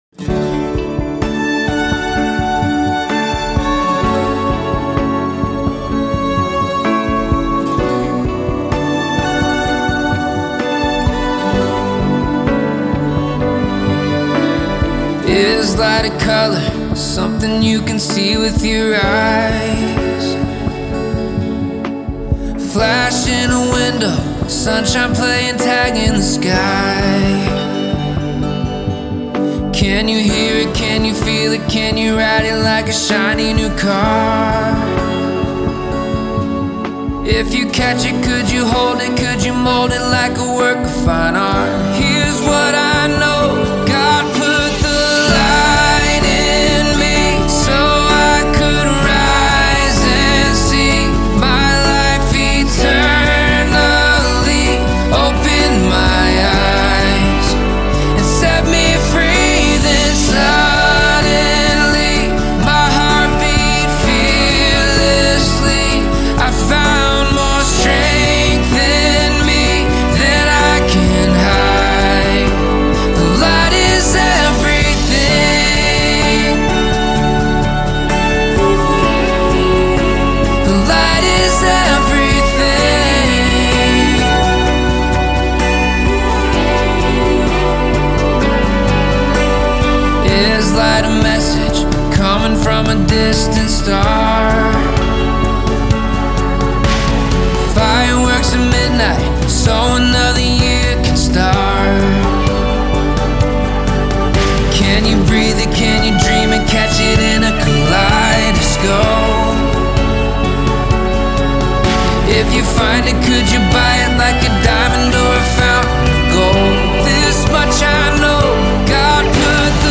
Gospel/Inspirational